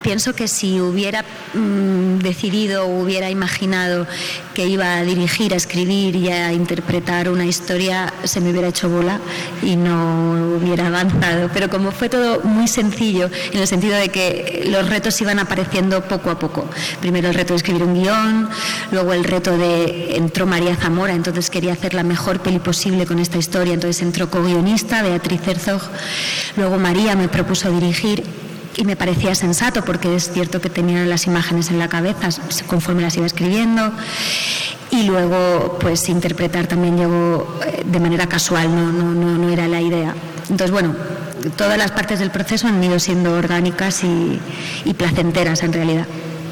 La actriz y directora Marta Nieto presenta la película en FICAL, acompañada de la también actriz Sonia Almarcha